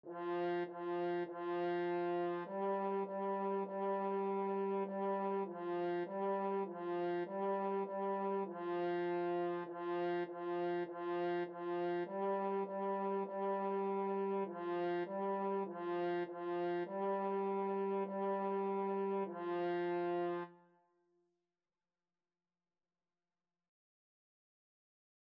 2/4 (View more 2/4 Music)
F4-G4
French Horn  (View more Beginners French Horn Music)
Classical (View more Classical French Horn Music)